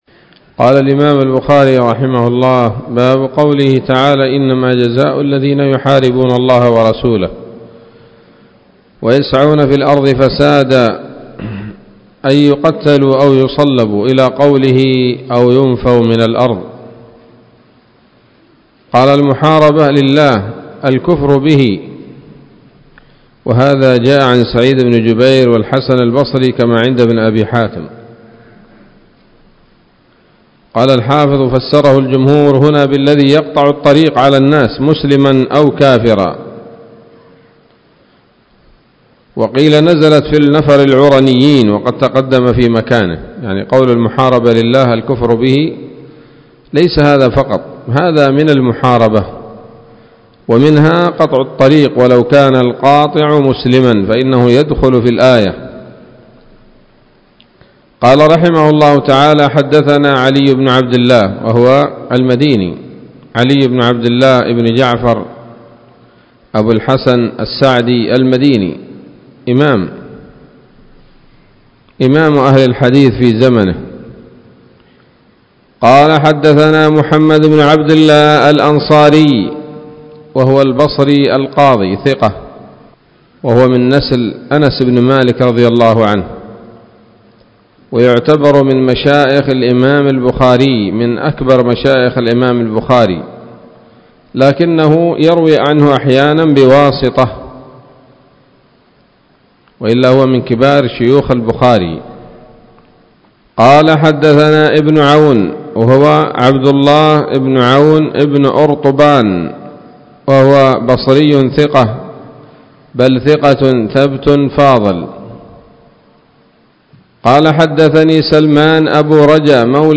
الدرس التاسع والثمانون من كتاب التفسير من صحيح الإمام البخاري